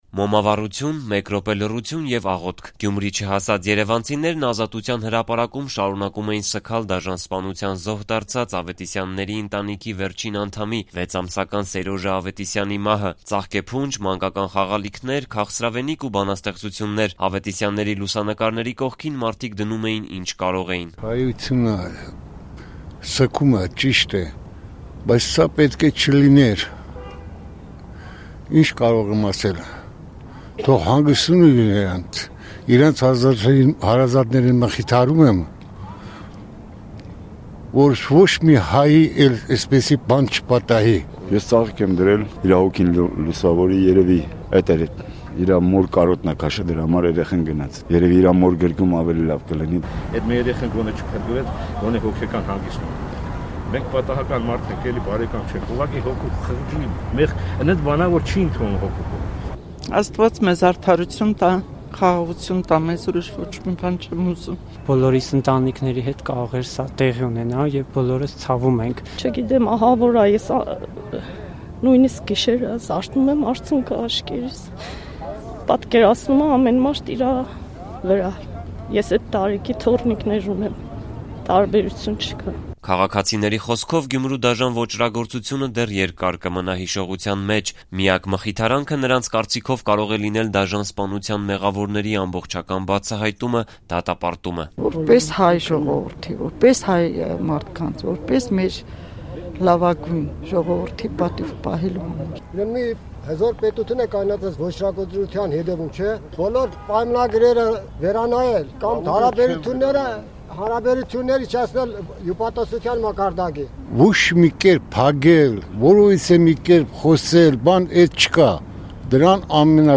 Մոմավառություն, 1 րոպե լռություն և աղոթք․․․